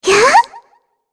Shea-Vox_Attack2_kr.wav